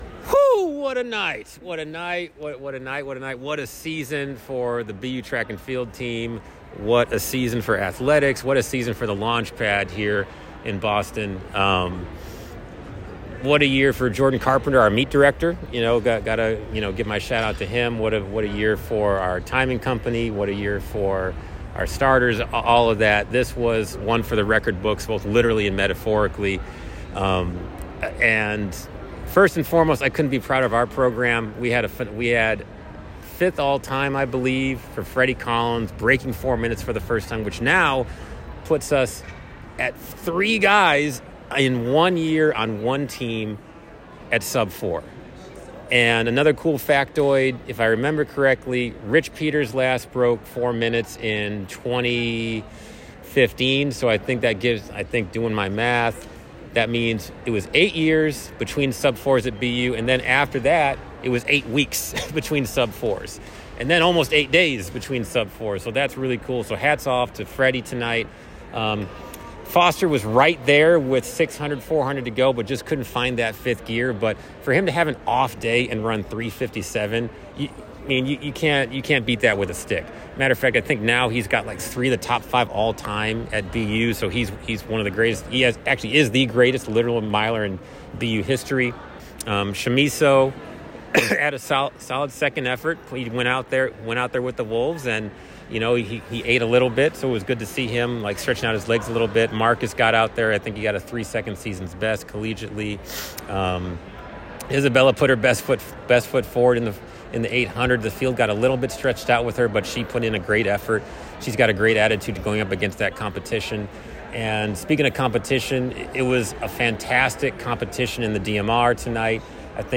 Track & Field / Terrier DMR Challenge Postmeet